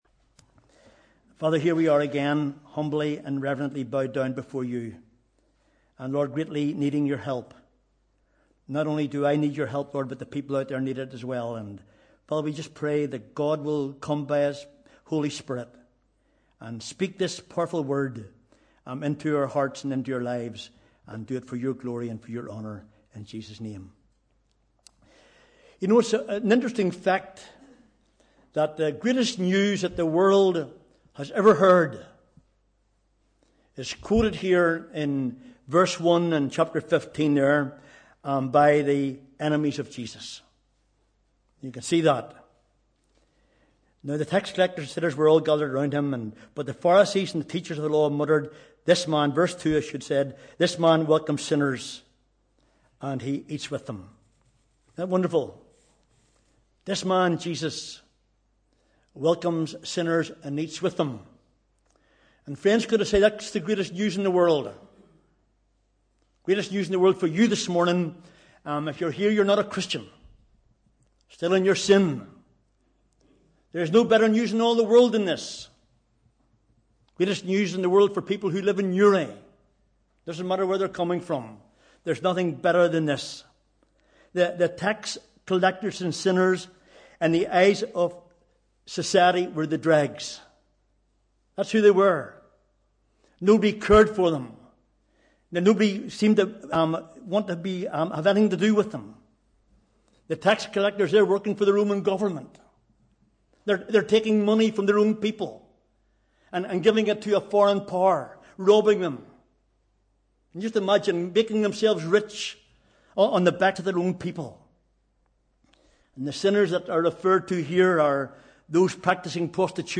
Sunday 20th August 2017 – Morning Service